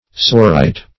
Search Result for " saussurite" : The Collaborative International Dictionary of English v.0.48: Saussurite \Saus"sur*ite\, n. [F. So called from M. Saussure.]
saussurite.mp3